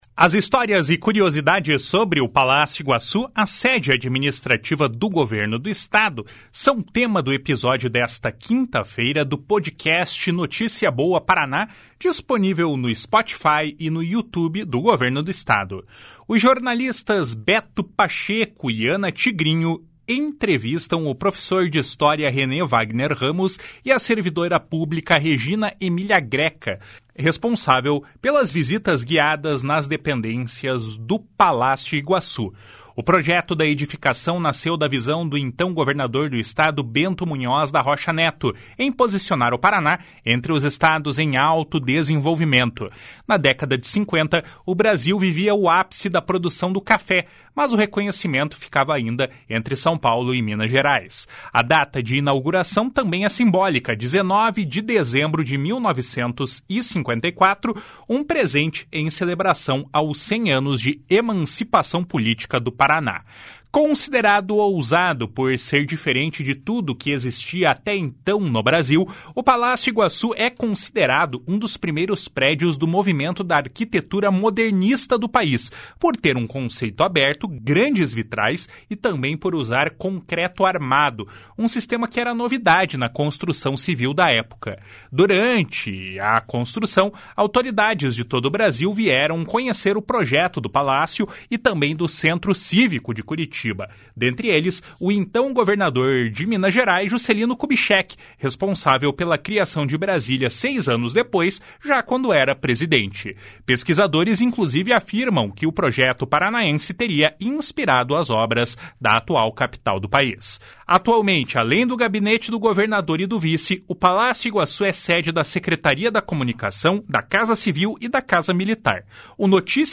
As histórias e curiosidades sobre o Palácio Iguaçu, sede administrativa do Governo do Estado, são tema do episódio desta quinta-feira, do podcast Notícia Boa Paraná, disponível no Spotify e no youtube do Governo do Paraná. Os jornalistas
entrevistam